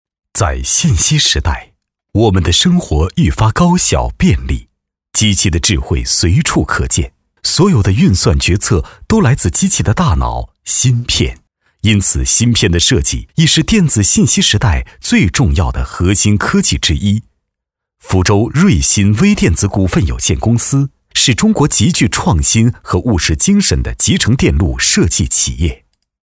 产品解说男18号
科技感 产品解说
高端青年男配，可大气沉稳，也可清亮有质感。